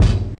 Cowboy kick.wav